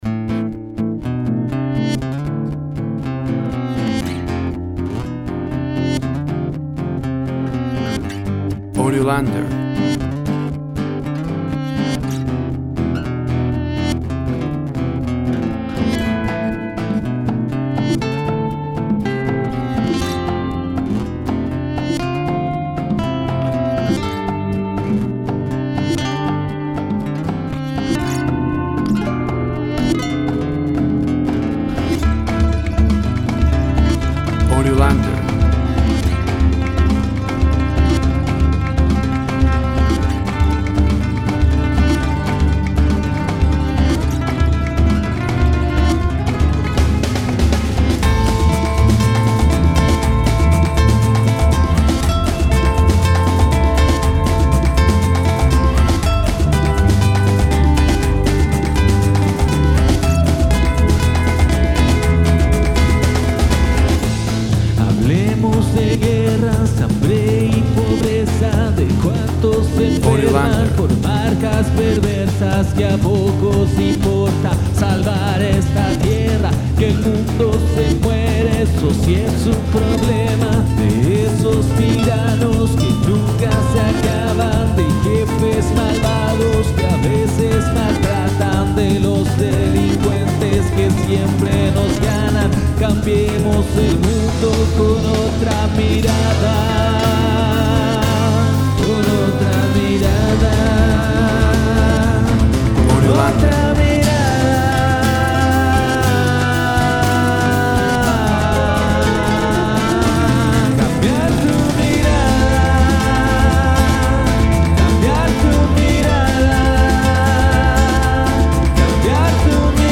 Folk rock experimental.
Tempo (BPM) 118